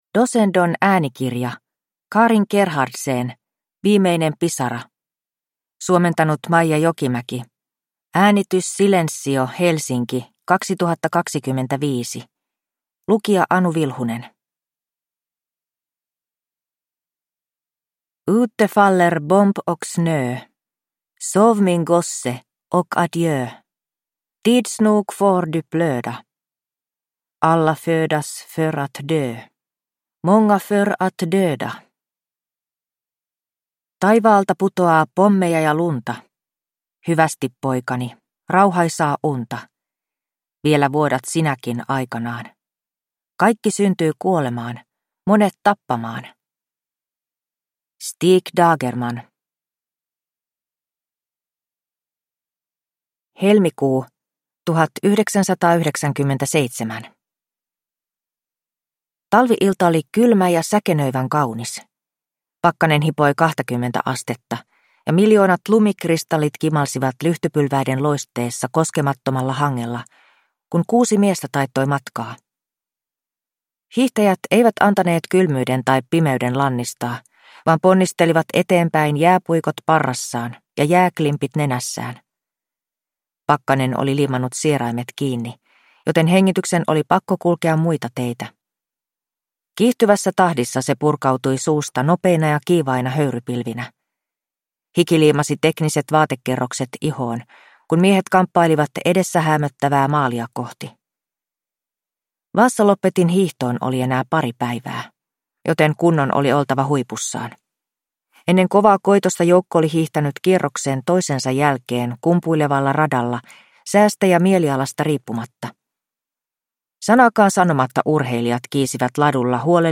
Viimeinen pisara – Ljudbok